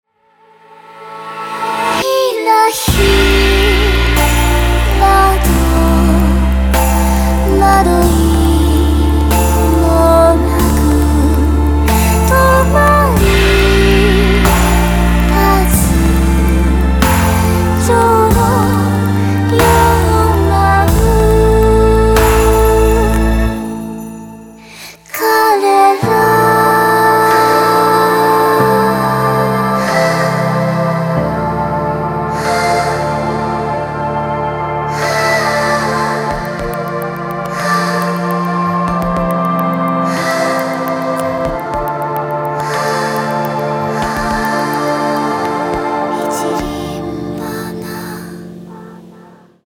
• Качество: 320, Stereo
атмосферные
мелодичные
спокойные
красивый женский голос
Спокойная песня на Японском